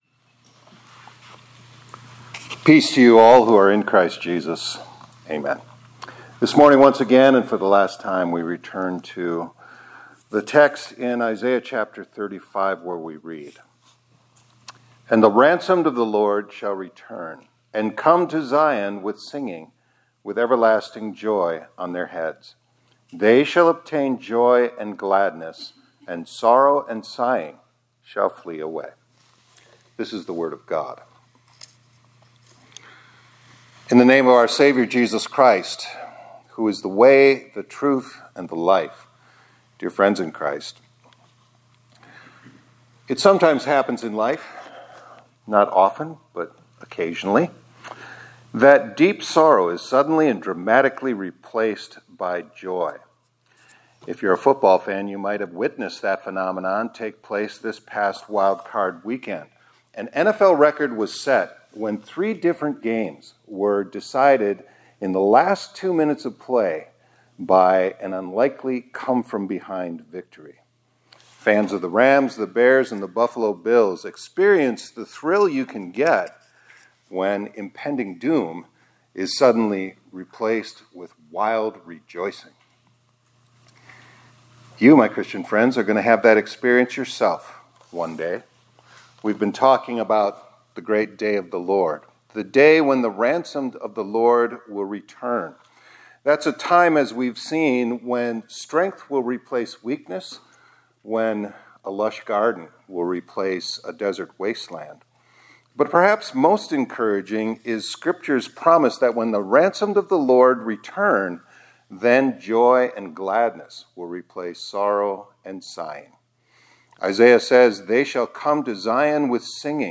2026-01-14 ILC Chapel — WHEN THE RANSOMED OF THE LORD RETURN — Joy and Gladness Will Replace Sorrow and Sighing